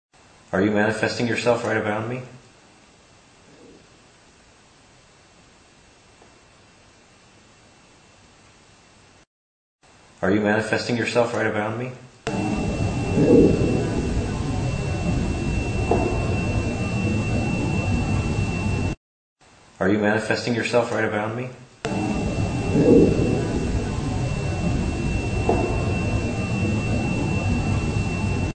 No - This was also recorded in the hospital rooms, right after I saw the light and right after the orb appears on the video above.
Original x1, cleaned and amp x1. Fairly easy to hear.